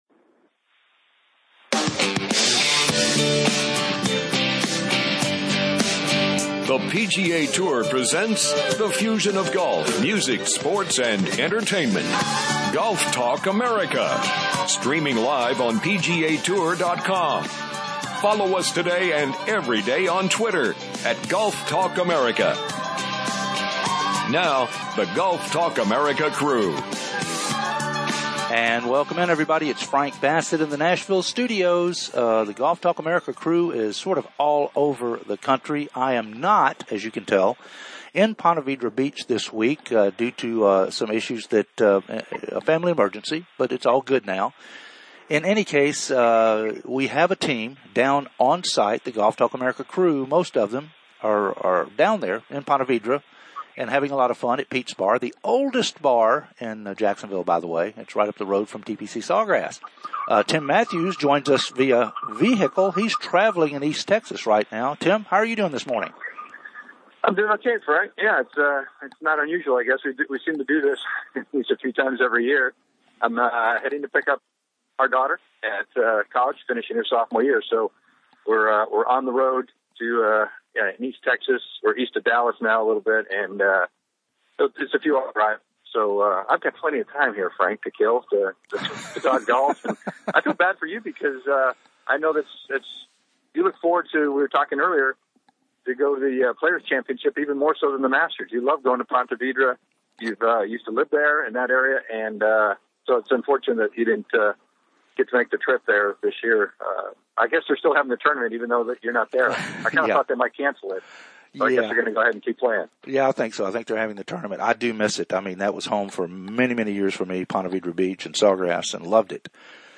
"LIVE" from THE PLAYERS